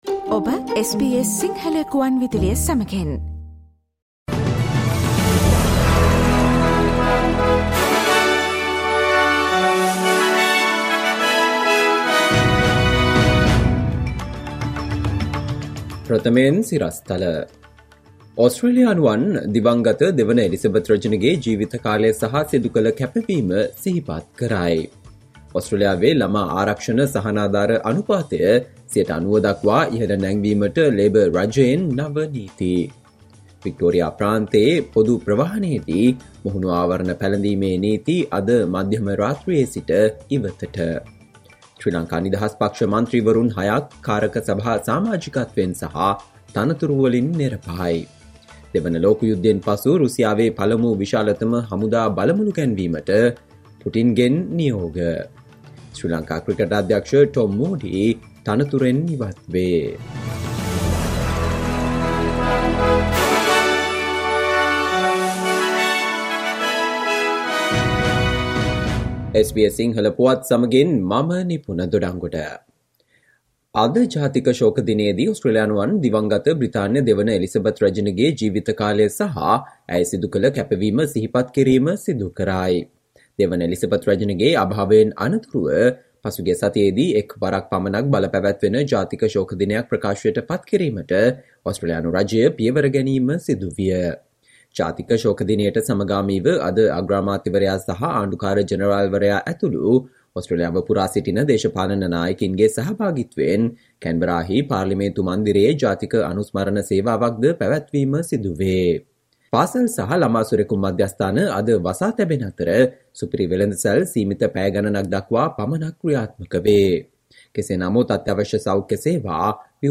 Listen to the SBS Sinhala Radio news bulletin on Thursday 22 September 2022